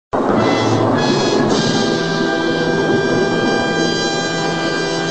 Звуки удивления, восторга
Музыкальная заставка удивления